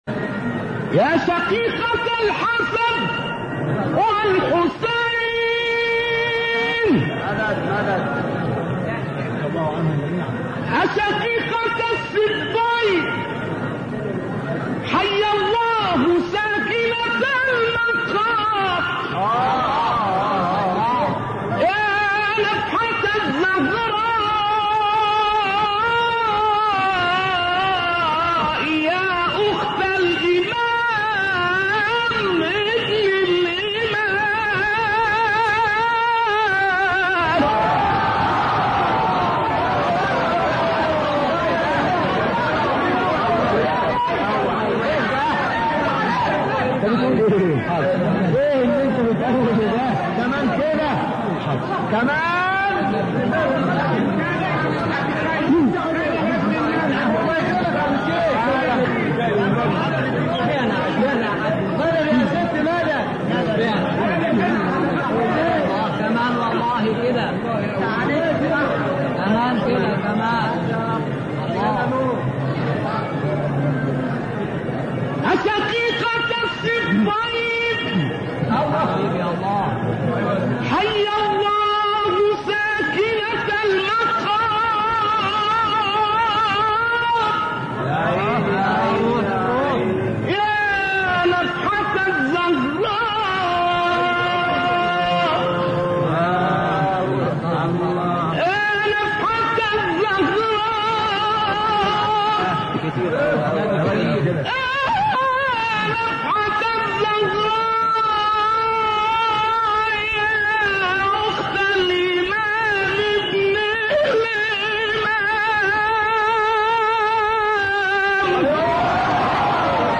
ابتهال نقشبندی در وصف «حضرت زینب(س)»
گروه شبکه اجتماعی: ابتهال «يا شقيقة الحسن و الحسين» با صوت سید‌محمد نقشبندی در وصف جضرت زینب کبری(س) را می‌شنوید.
به گزارش خبرگزاری بین‌المللی قرآن(ایکنا) «يا شقيقة الحسن و الحسين» نام ابتهال شنیدنی از سیدمحمد نقشبندی، مبتهل و قاری برجسته مصری است که در کانال تلگرامی نغمه‌های بهشتی منتشر شده است.